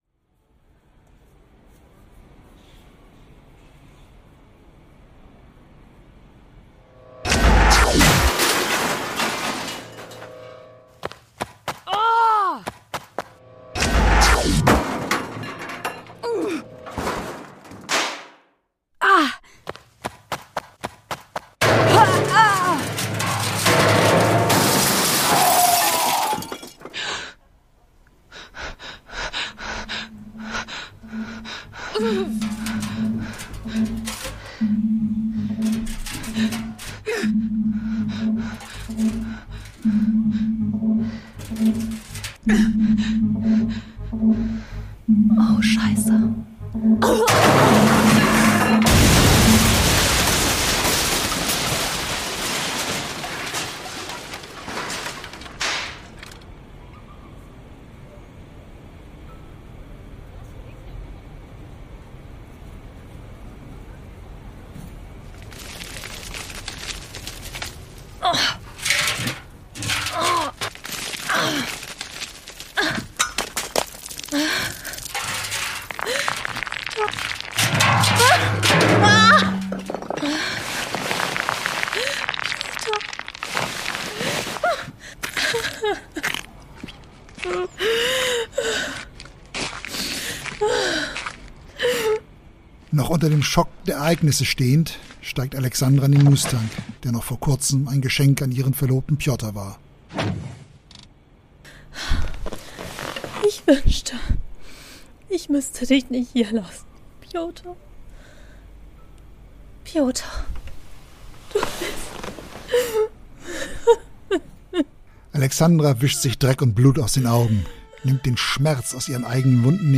Das Hörspiel der Podcaster